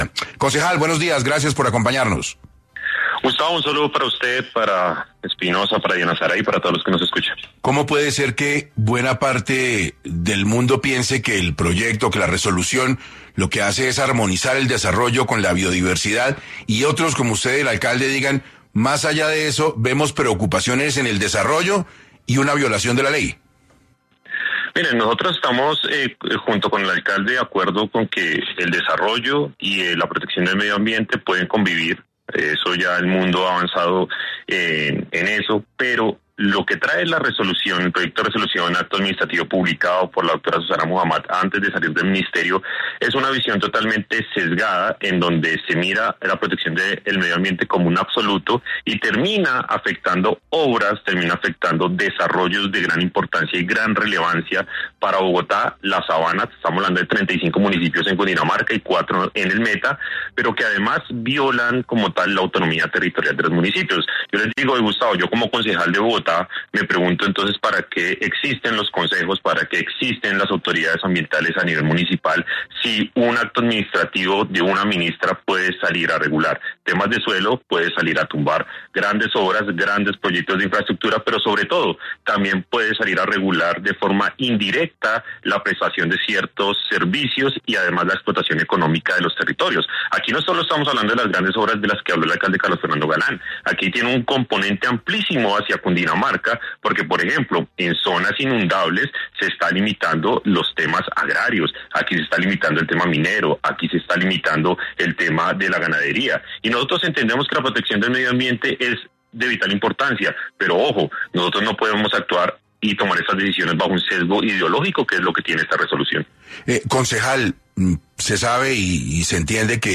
En entrevista para 6AM, el concejal, Daniel Briceño, habló sobre las afectaciones que podría traer el proyecto ambiental a la Sabana de Bogotá.